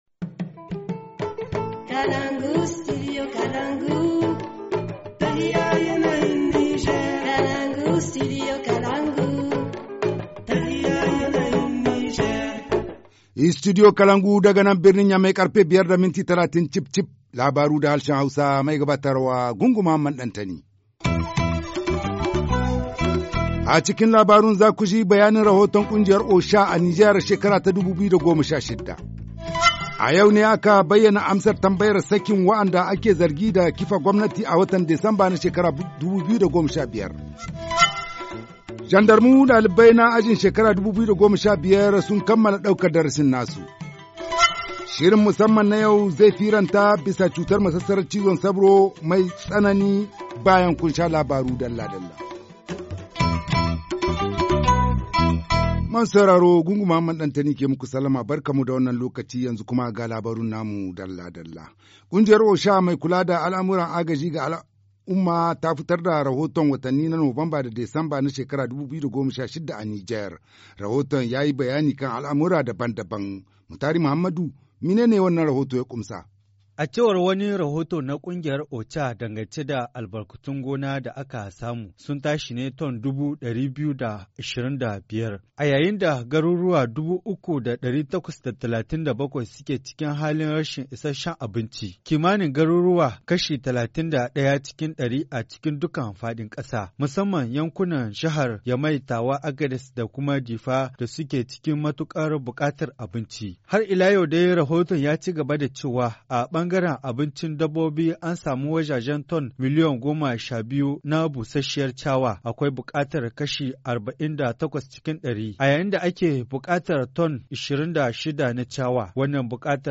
Journal du 6 janvier 2017 - Studio Kalangou - Au rythme du Niger